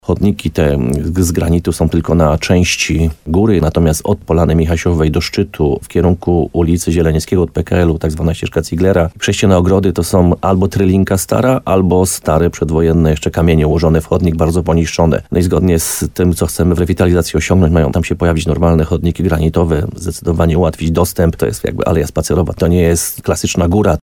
– Całość ma być przystosowana do przewożenia dzieci w wózkach, czy nawet dla niepełnosprawnych – mówi burmistrz Piotr Ryba.